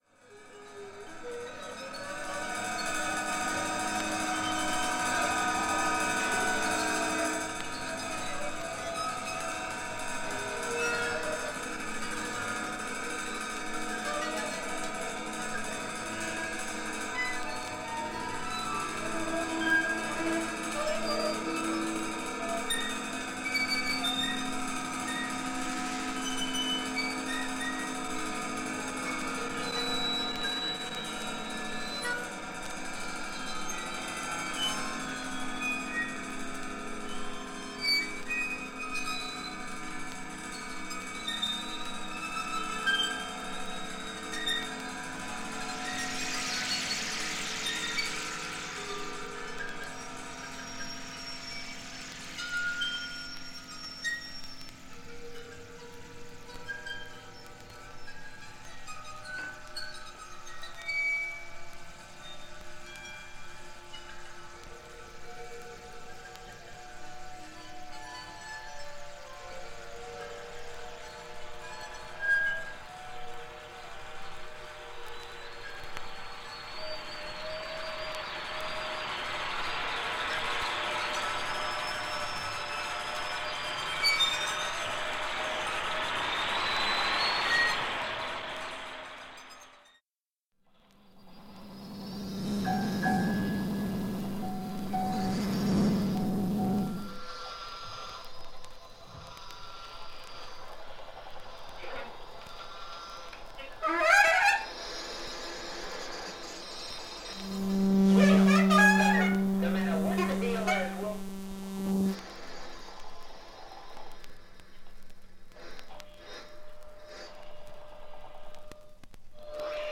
EXPERIMENTAL
自然の中に混じる人間達の喧騒を表現したのかな？